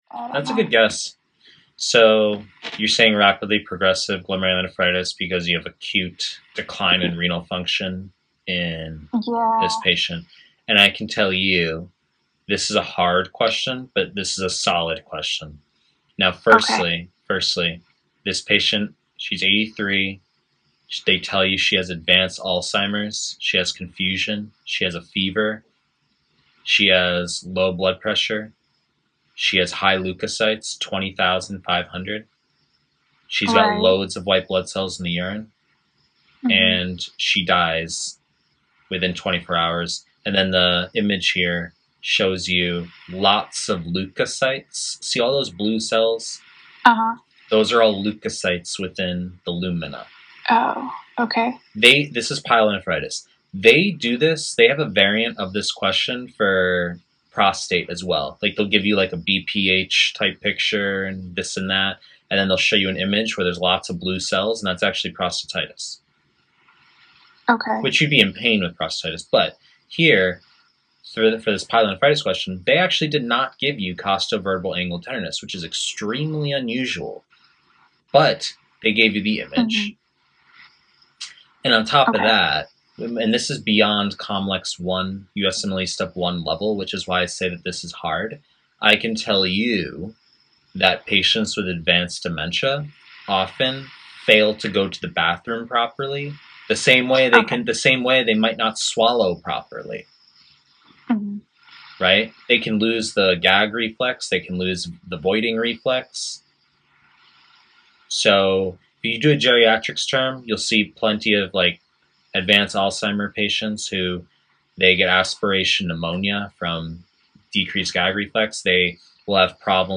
Pre-recorded lectures / Renal